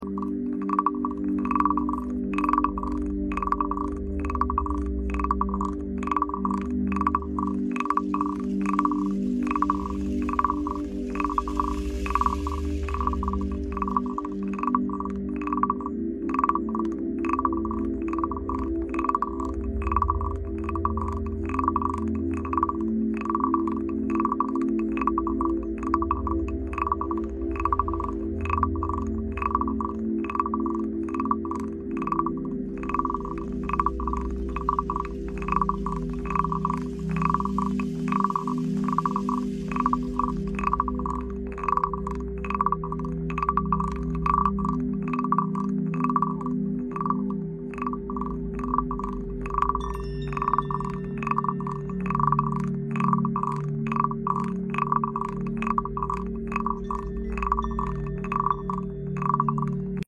💸 Money Block Remover ASMR sound effects free download